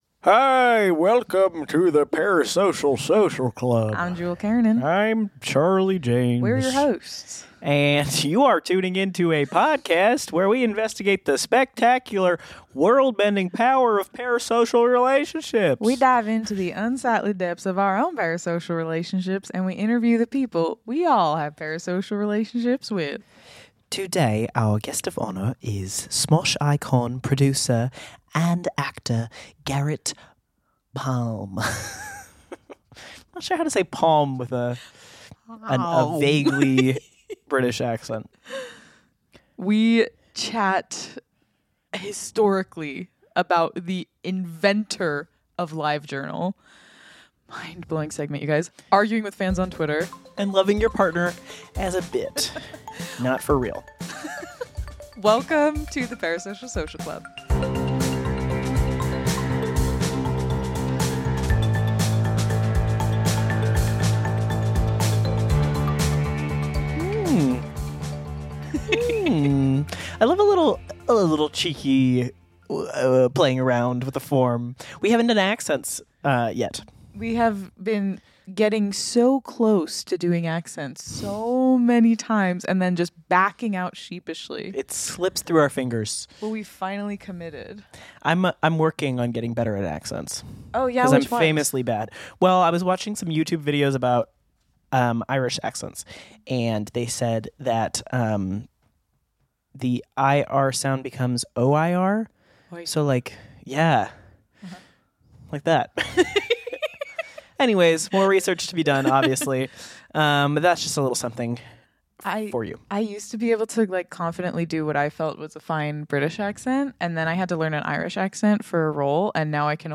… continue reading 15 episoder # Society # Comedy # Parasocial Social Club